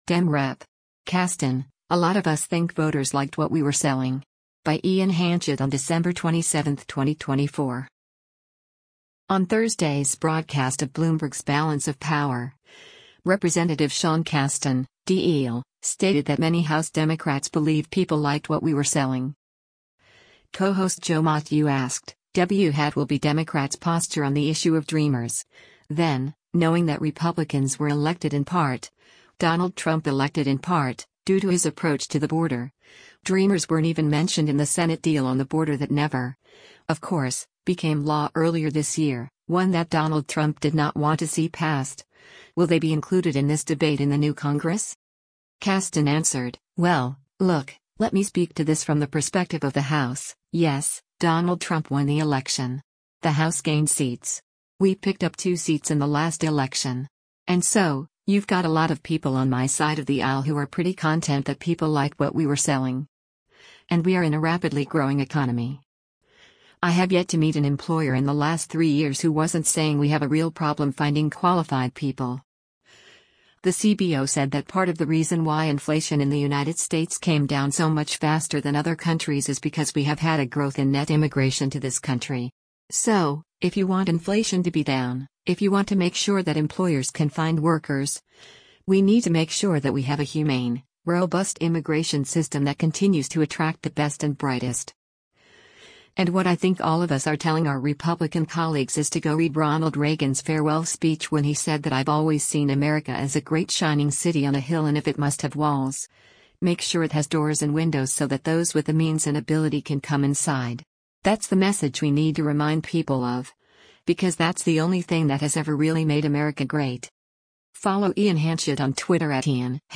On Thursday’s broadcast of Bloomberg’s “Balance of Power,” Rep. Sean Casten (D-IL) stated that many House Democrats believe “people liked what we were selling.”